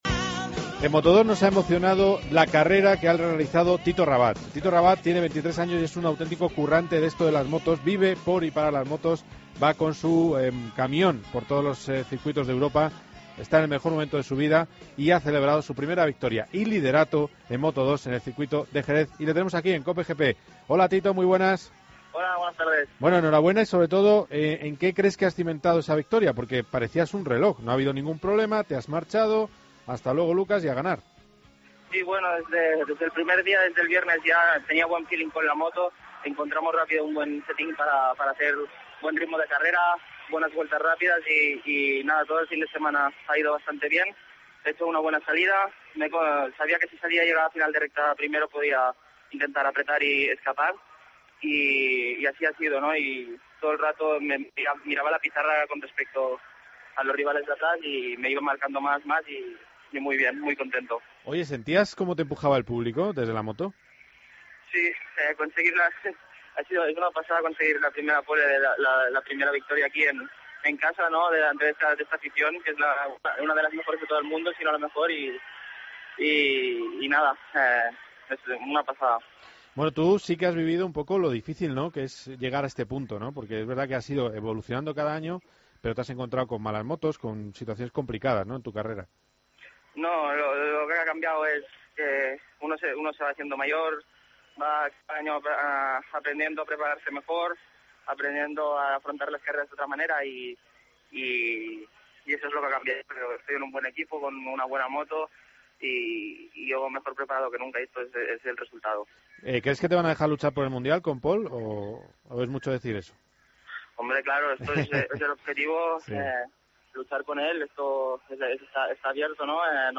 Hablamos con Tito Rabat, ganador de Moto 2 en Jerez.